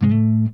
OCTAVE 1.wav